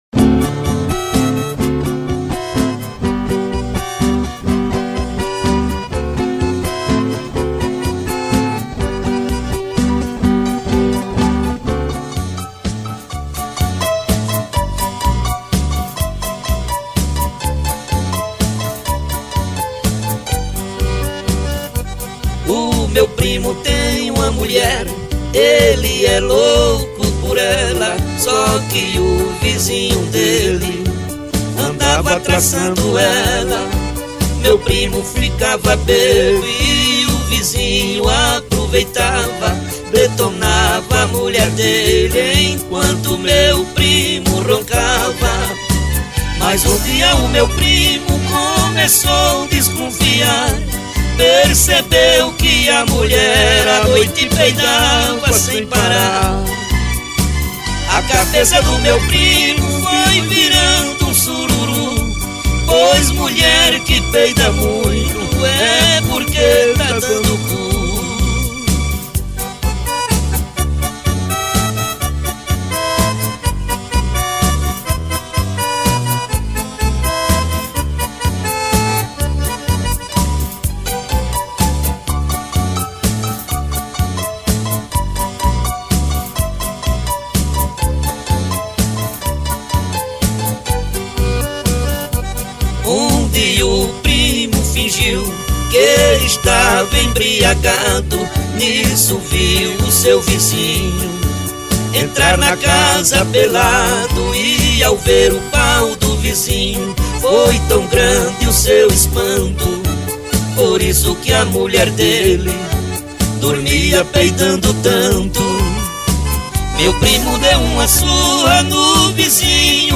2024-03-24 15:58:54 Gênero: Sertanejo Views